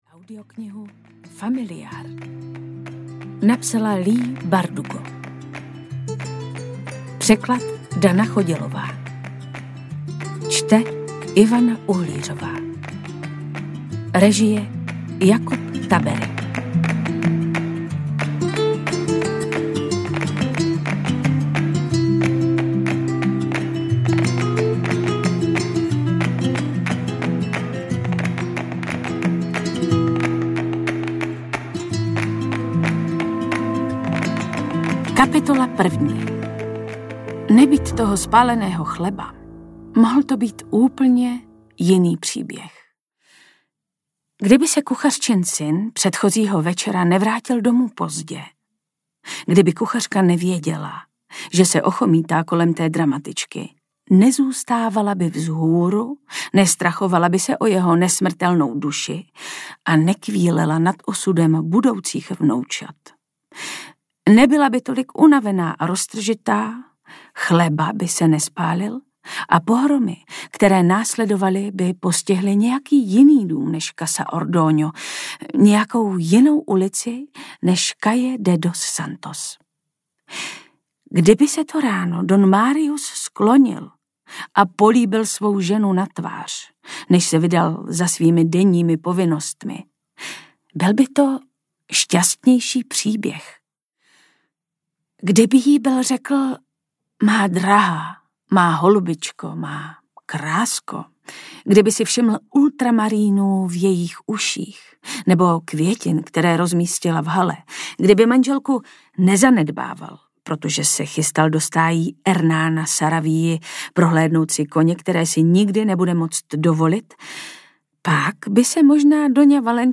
Familiár audiokniha
Ukázka z knihy